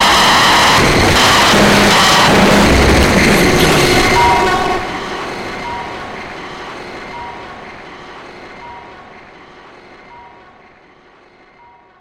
Sound Effects
In-crescendo
Thriller
Atonal
ominous
eerie
synth
ambience
pads